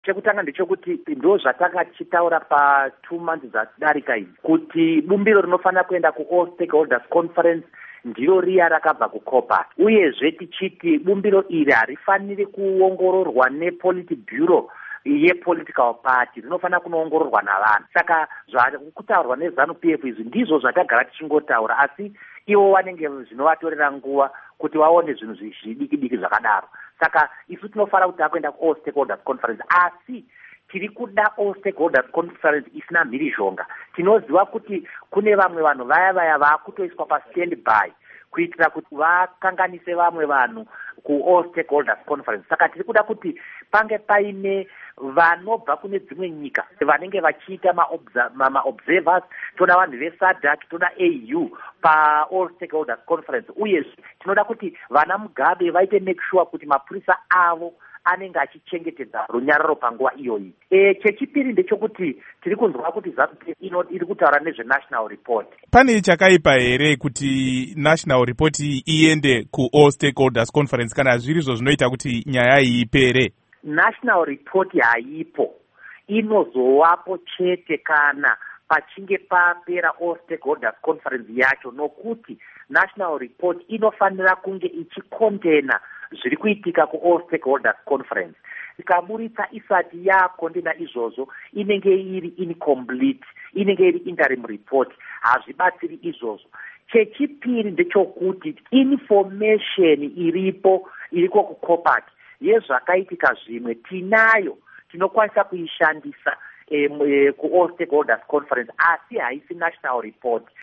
Hurukuro naVaRugare Gumbo